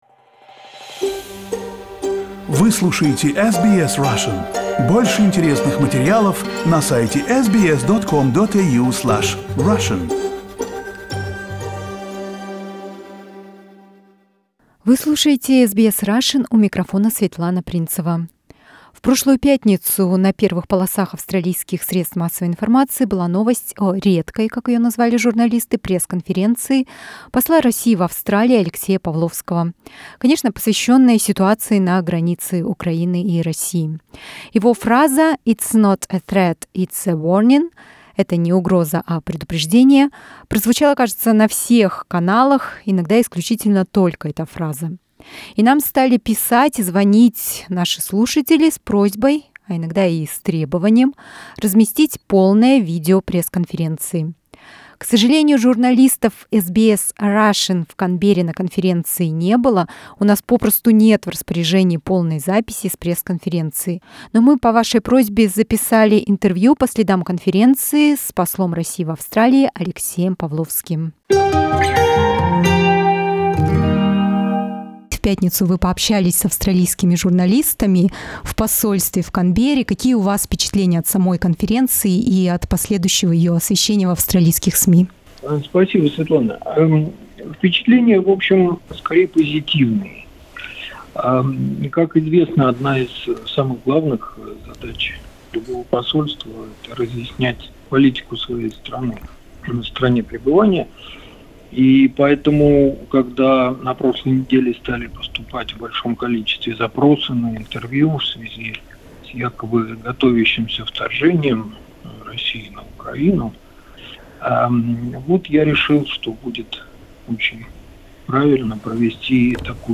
Interview with Russian Ambassador to Australia Alexey Pavlovsky
Last Friday, Russian Ambassador to Australia, Dr. Alexey Pavlovsky, held a press conference for the Australian media. We recorded an interview with Dr. Pavlovsky following the press conference.